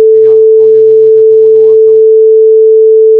Le second cliquer-ici  "voixperturbée.wav" est une voix  inaudible à cause du son précédent perturbateur.
voix-perturbée.wav